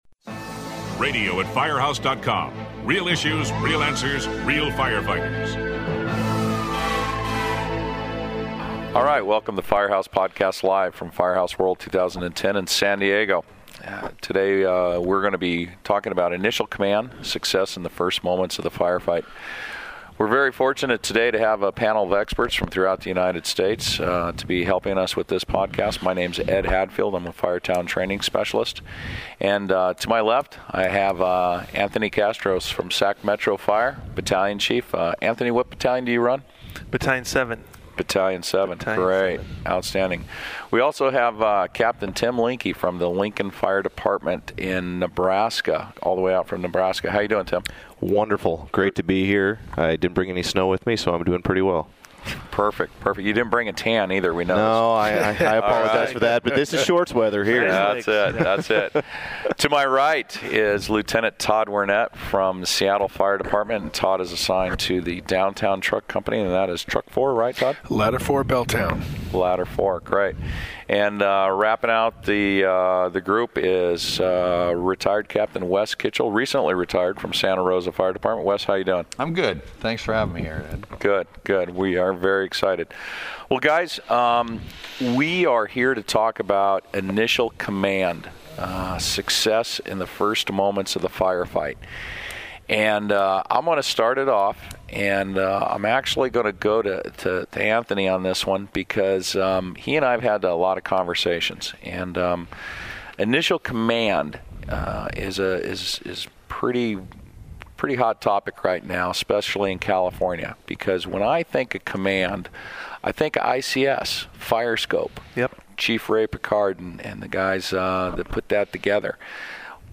This podcast was recorded at Firehouse World in March.